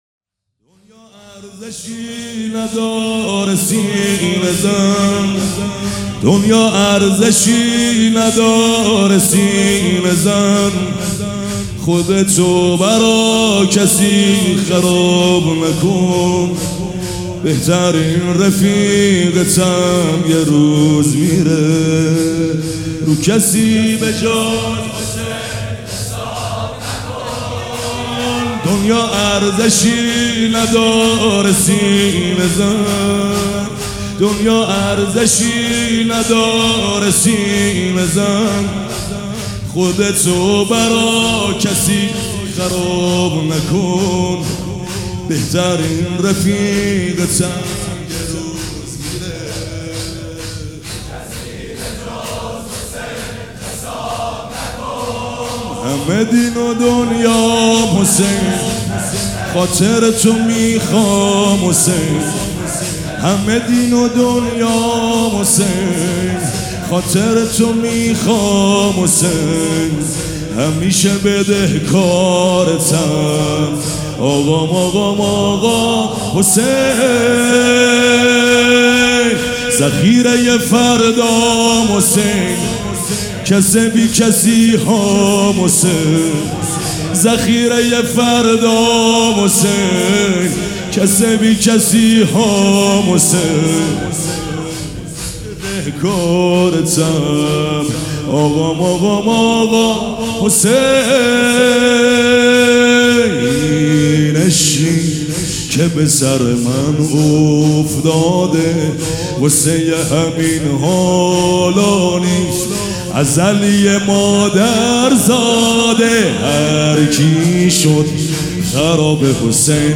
شور بخش دوم
حسینیه ریحانة‌الحسین (س)
سبک اثــر شور